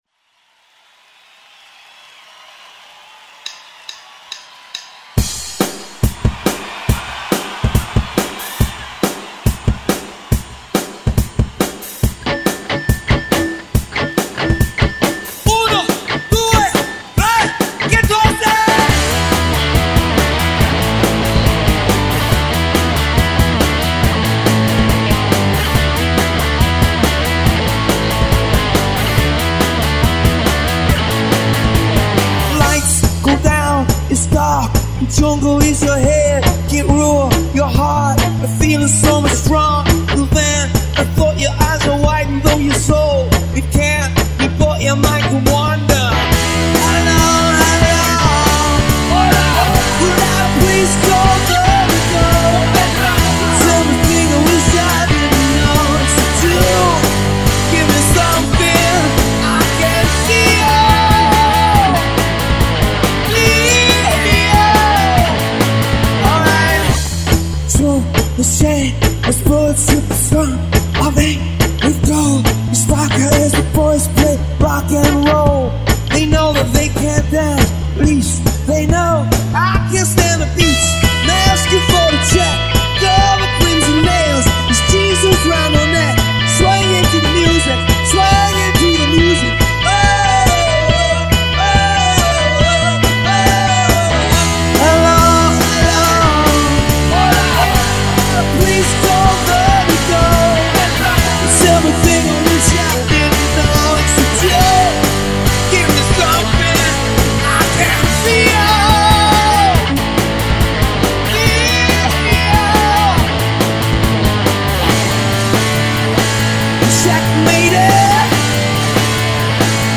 Multi-track recording